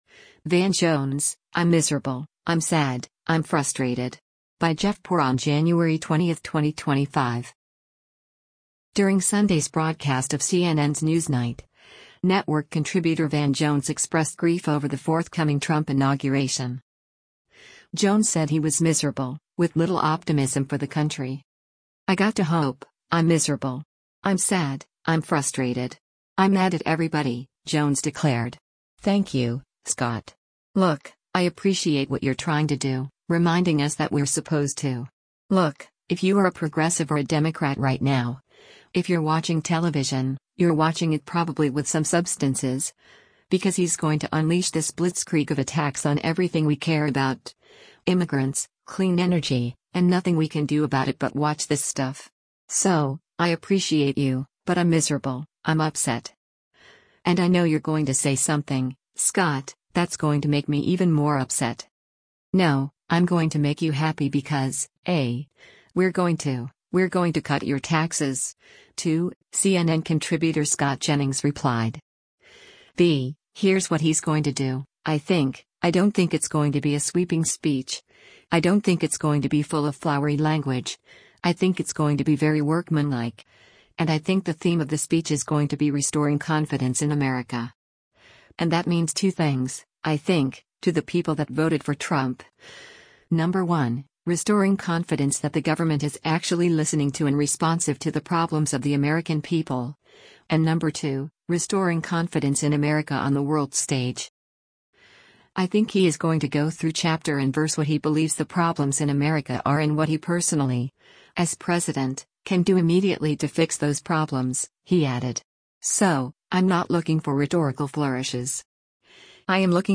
During Sunday’s broadcast of CNN’s “Newsnight,” network contributor Van Jones expressed grief over the forthcoming Trump inauguration.